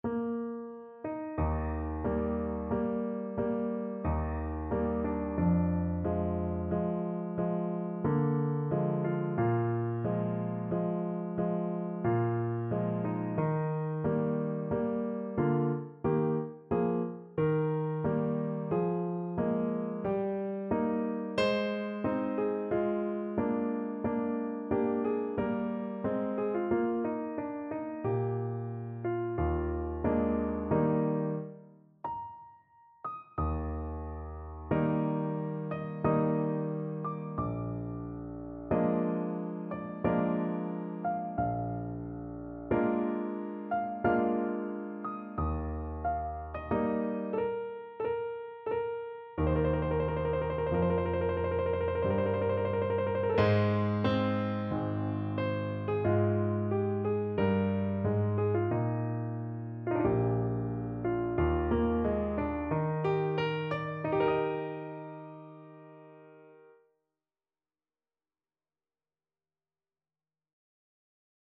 No parts available for this pieces as it is for solo piano.
Eb major (Sounding Pitch) (View more Eb major Music for Piano )
3/4 (View more 3/4 Music)
Adagio =45
Instrument:
Piano  (View more Intermediate Piano Music)
Classical (View more Classical Piano Music)
beethoven_trio-in-b-flat-major_2nd_PNO.mp3